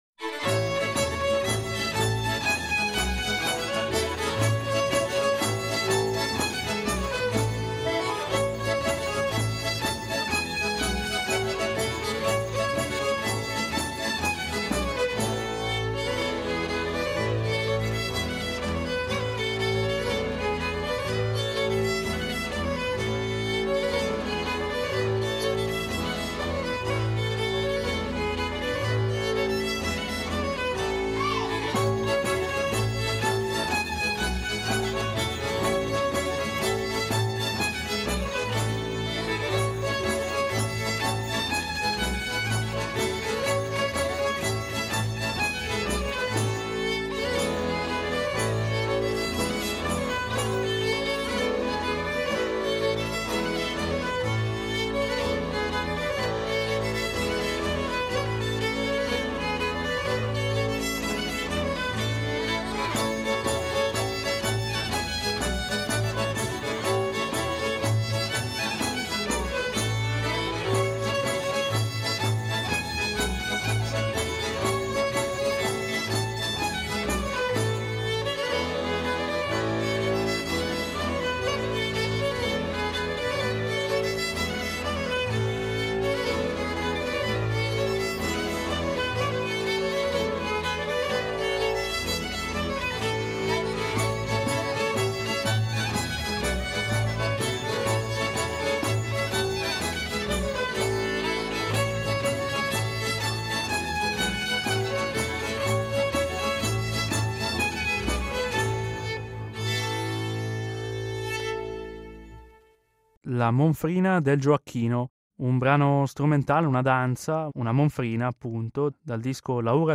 In dialogo con la Vox Blenii